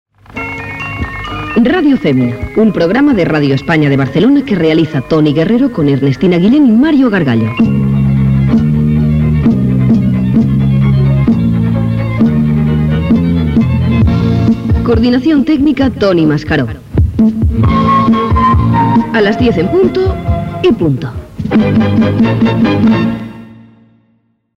Radio Fémina: careta - Radio España de Barcelona